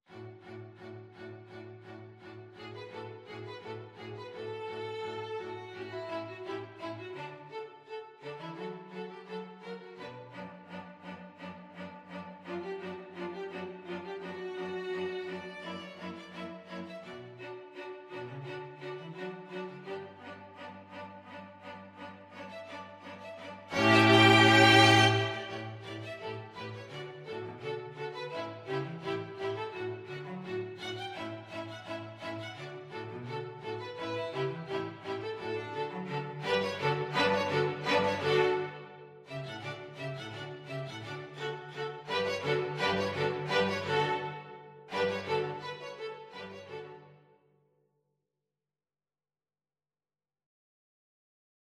Free Sheet music for String Quartet
Violin 1Violin 2ViolaCello
4/4 (View more 4/4 Music)
D major (Sounding Pitch) (View more D major Music for String Quartet )
= 85 Allegro scherzando (View more music marked Allegro)
Classical (View more Classical String Quartet Music)